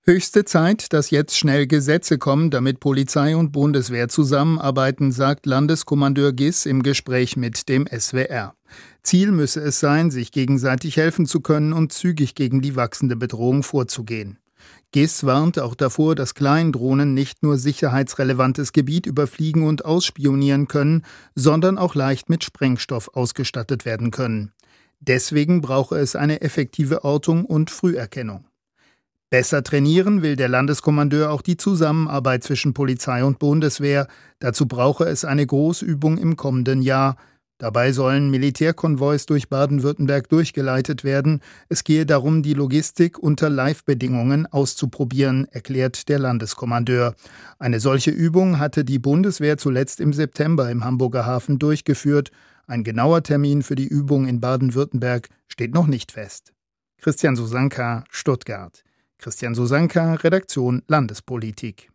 Wie sich Baden-Württemberg vorbereiten muss und welche Bedrohung von Drohnen ausgeht: Der oberste Soldat im Land, BW-Landeskommandeur Michael Giss, im Interview.